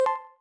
Add some more sound effects